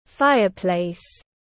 Lò sưởi tiếng anh gọi là fireplace, phiên âm tiếng anh đọc là /ˈfaɪə.pleɪs/
Fireplace /ˈfaɪə.pleɪs/